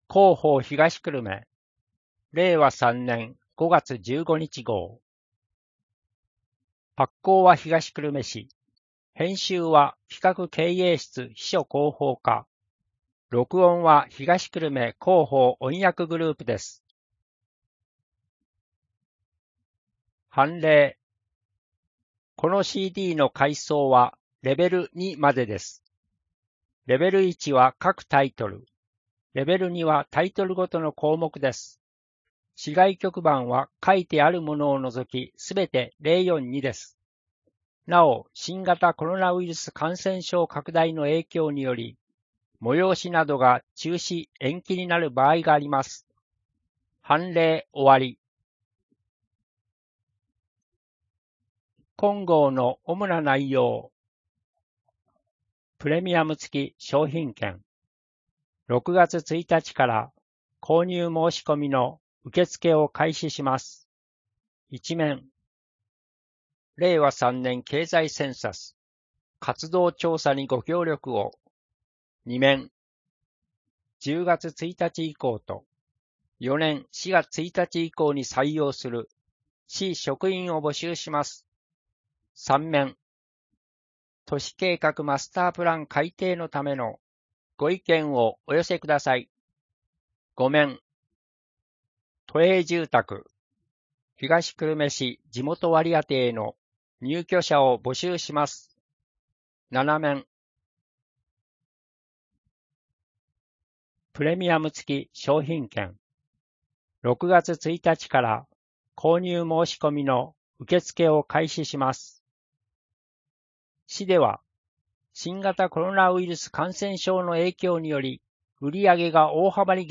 声の広報（令和3年5月15日号）